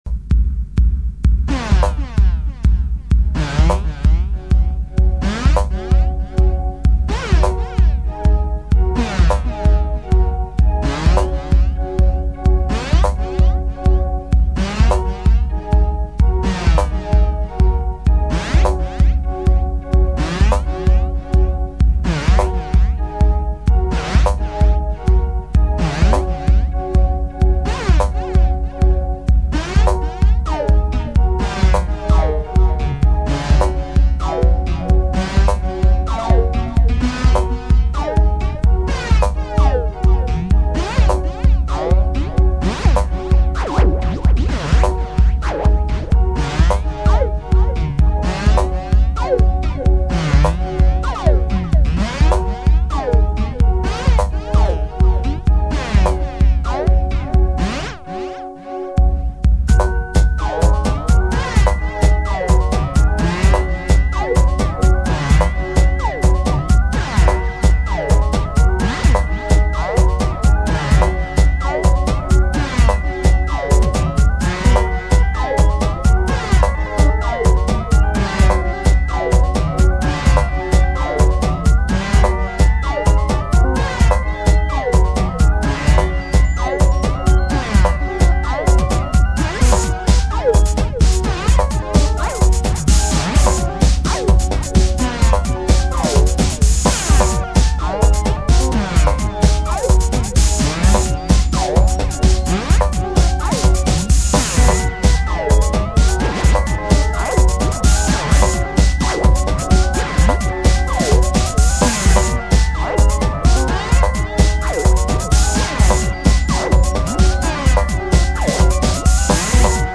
A stronger flanging effect, or a sense of strange space?